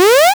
powerup.wav